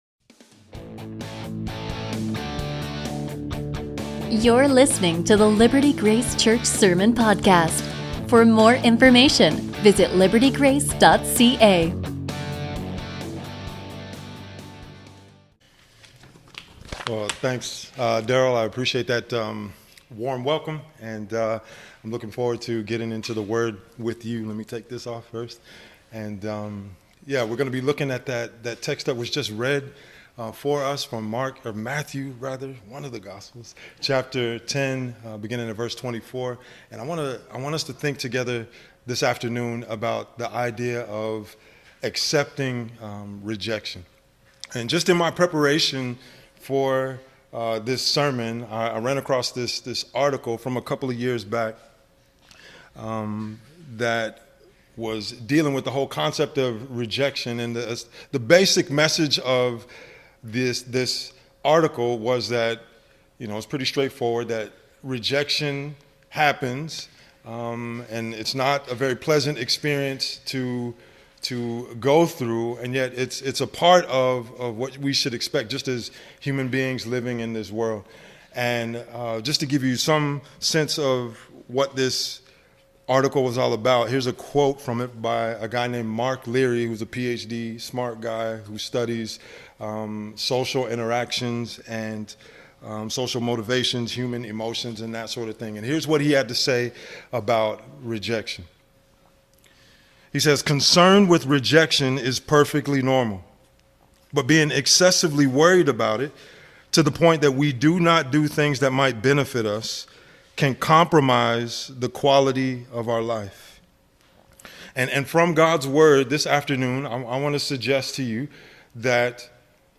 A message from the series "Standalone."
Sermons